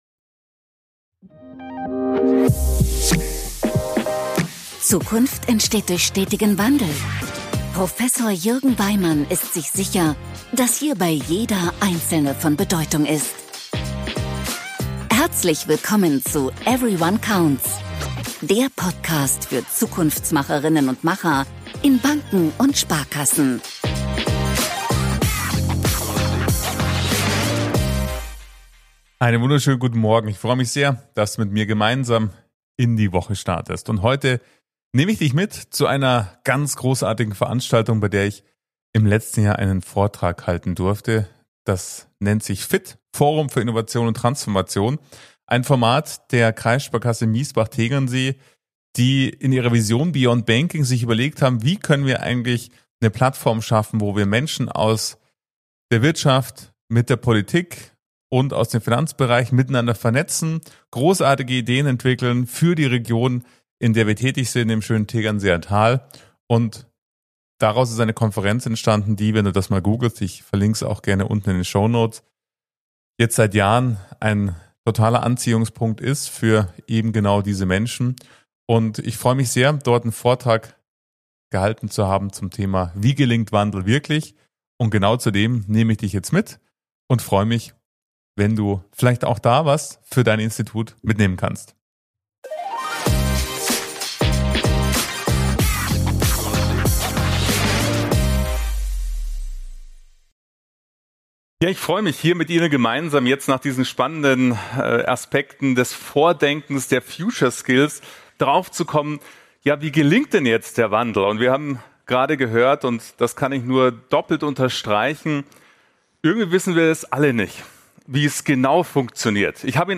Warum echte Transformation Gänsehaut braucht - Keynote auf dem Forum für Innovation und Transformation ~ Everyone Counts - Transformation für Banken und Sparkassen Podcast